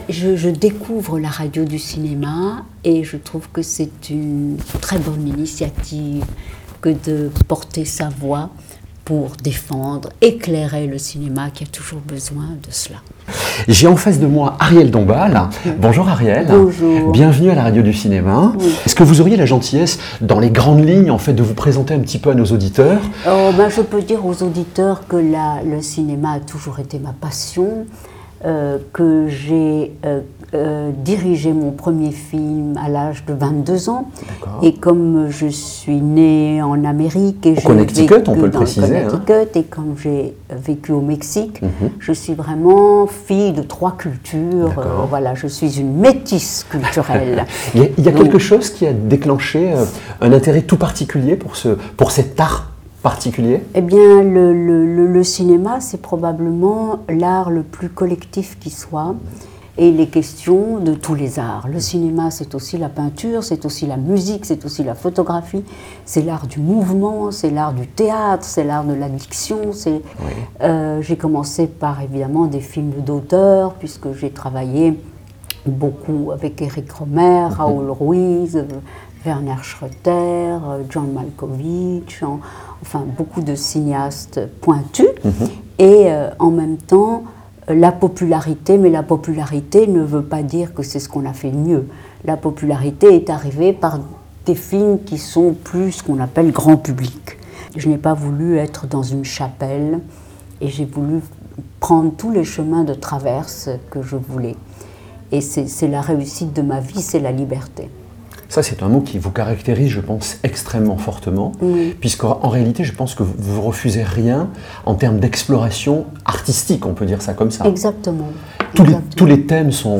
Un regard unique sur l'art et l'exploration du cinéma